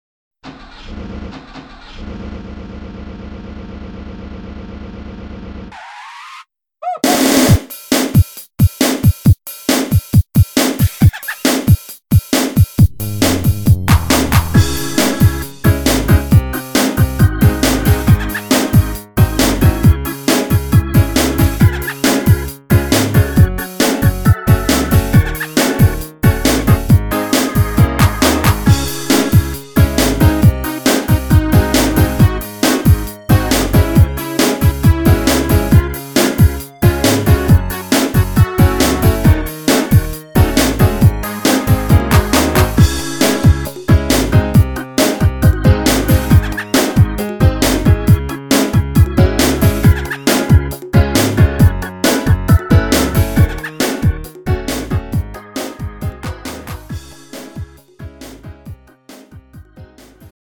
장르 가요 구분 Lite MR
Lite MR은 저렴한 가격에 간단한 연습이나 취미용으로 활용할 수 있는 가벼운 반주입니다.